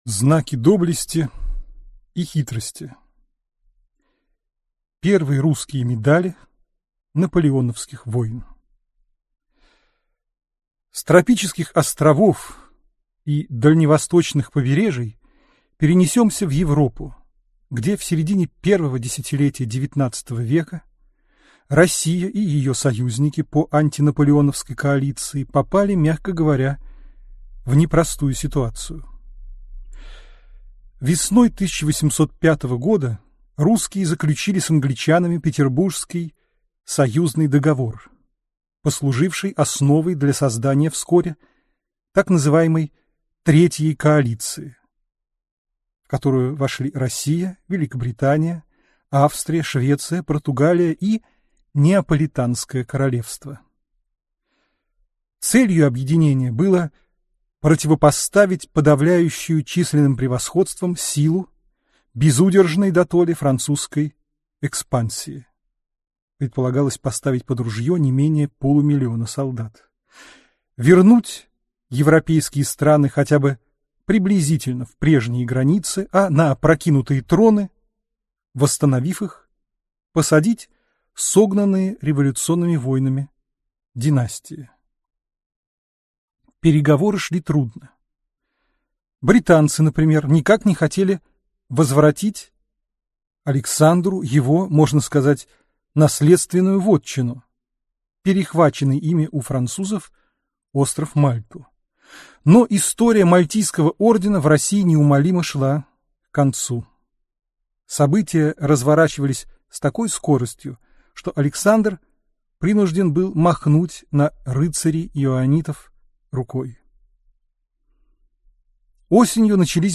В первые годы XIX века союзники по антинаполеоновской коалиции оказались в непростом положении. Из этой лекции вы узнаете, какие медали вручались в первые годы противостояния, до начала Отечественной...